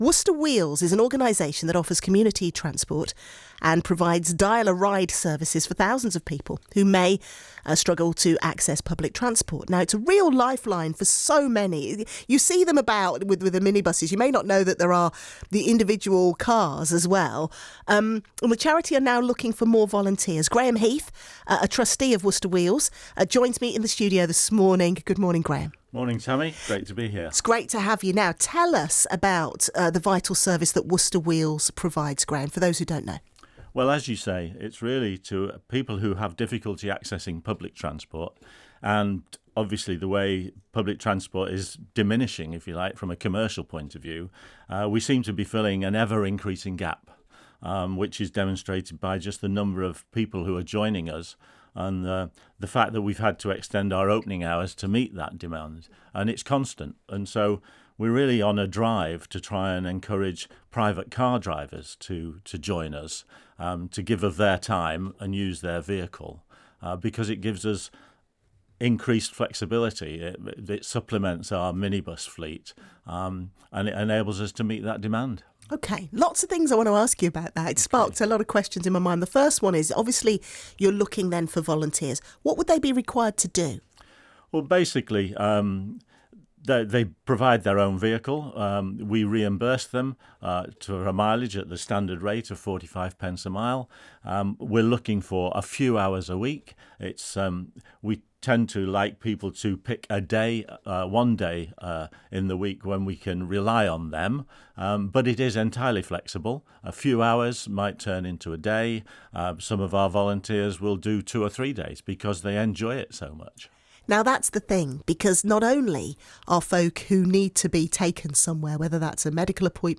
BBC Interview